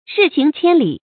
日行千里 注音： ㄖㄧˋ ㄒㄧㄥˊ ㄑㄧㄢ ㄌㄧˇ 讀音讀法： 意思解釋： 一天能走一千里。